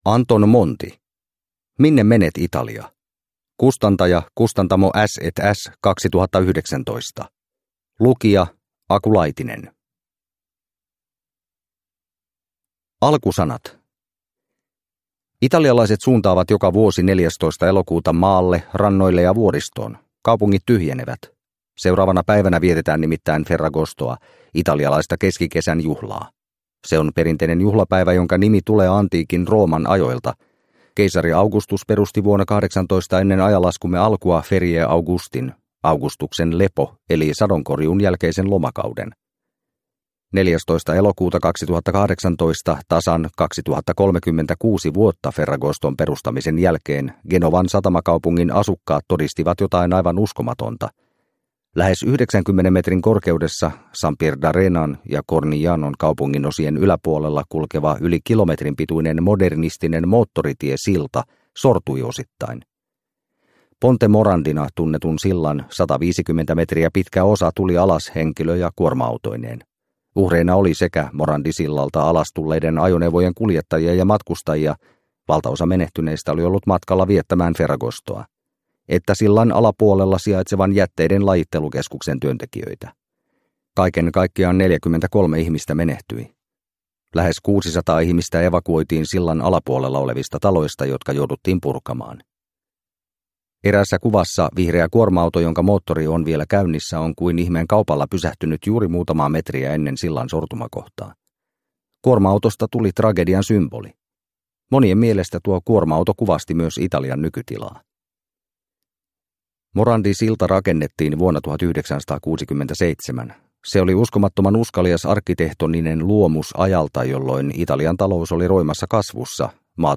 Minne menet, Italia? – Ljudbok – Laddas ner